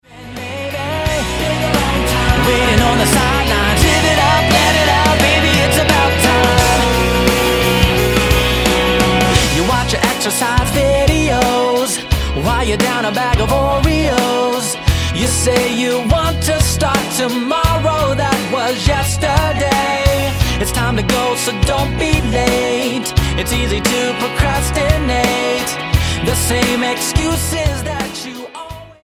STYLE: Pop
the gutsy mid tempo